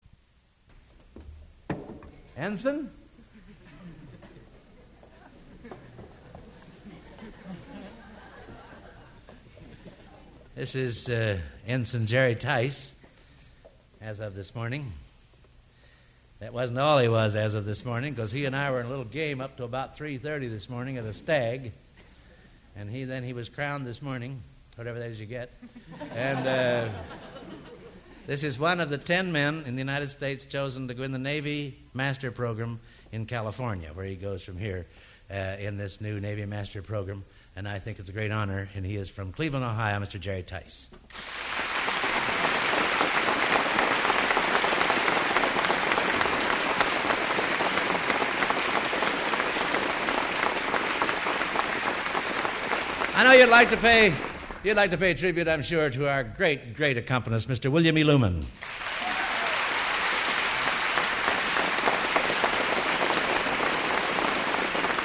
Location: West Lafayette, Indiana
Genre: | Type: End of Season